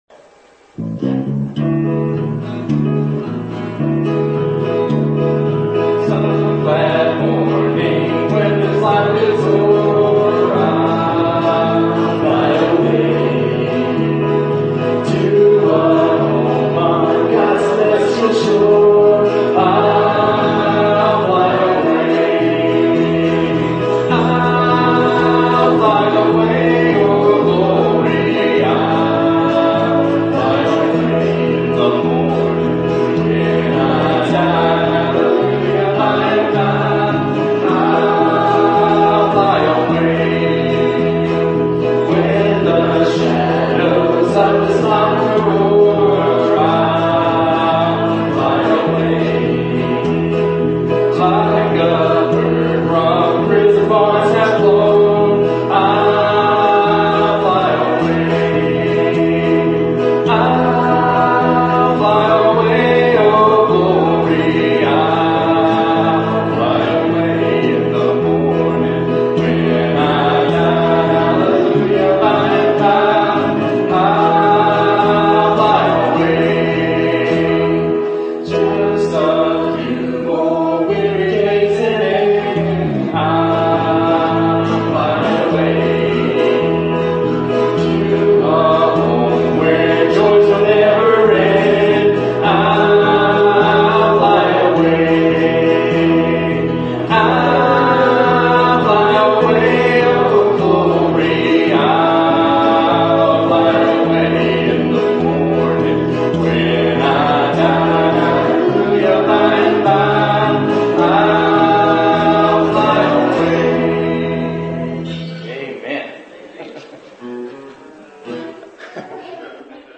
A recording of I'll Fly Away recorded at Good Hope Baptist on November 11, 2012